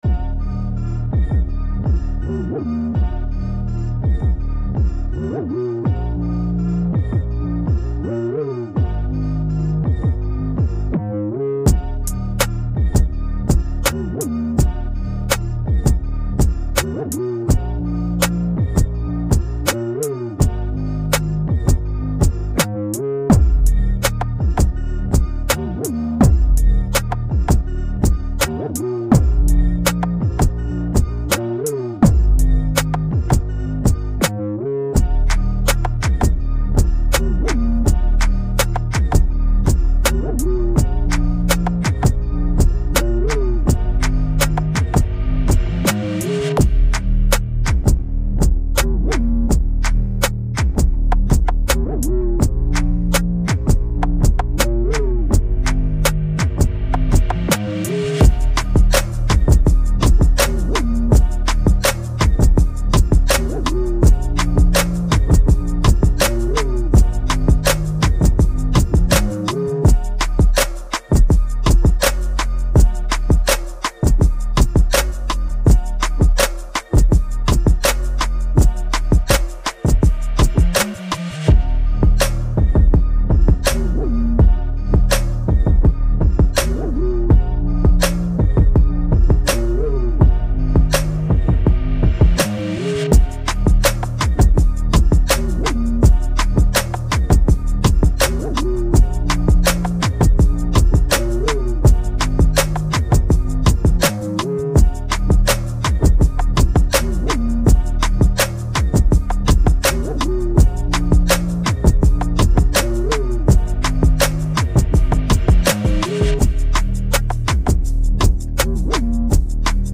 “Hip – hop/trap